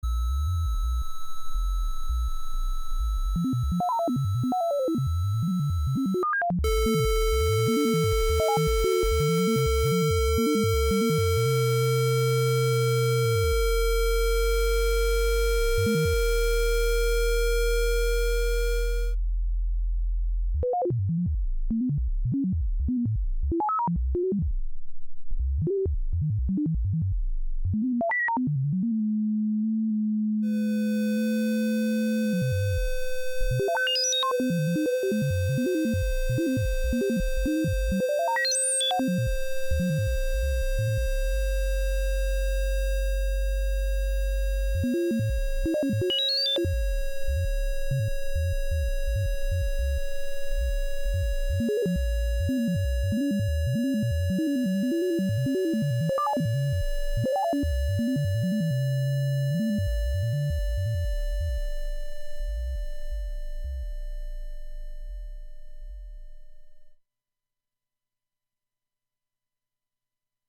ARCHIVE Live from the Thingularity Studios: Thingularity (Audio) Nov 29, 2023 shows Live from the Thingularity Studios the sound lab at Catskill Heights Play In New Tab (audio/mpeg) Download (audio/mpeg)